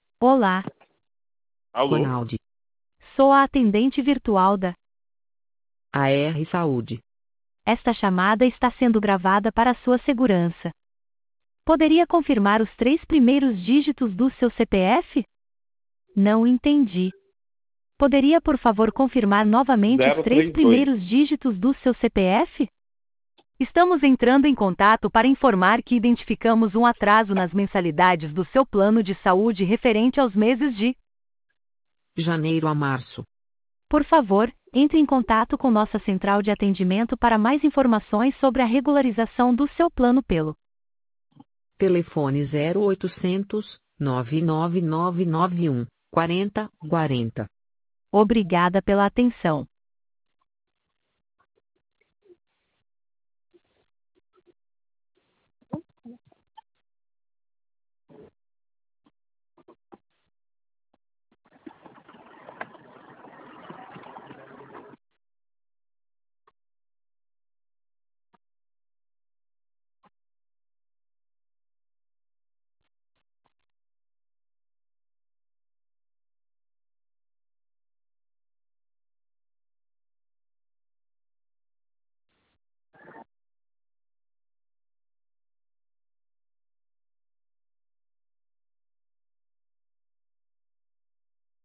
Exemplo da voz